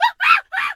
monkey_2_scream_07.wav